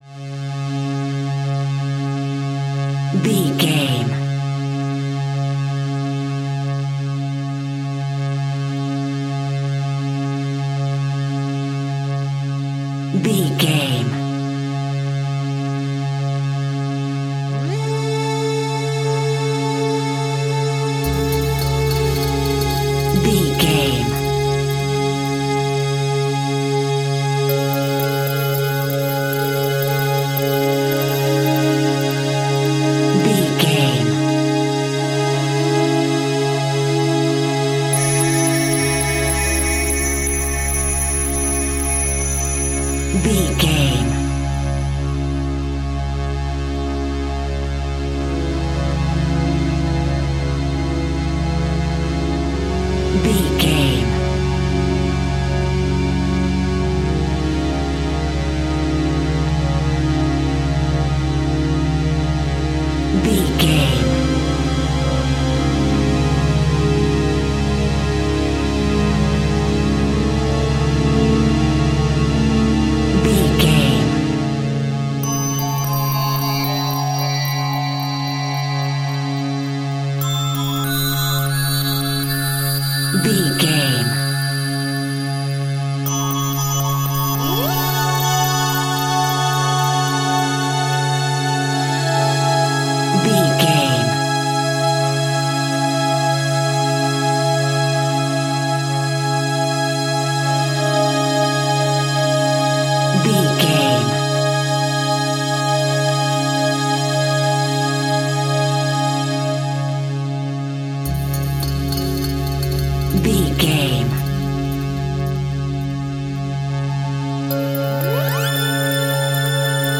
Ionian/Major
D♭
tension
suspense
piano
synthesiser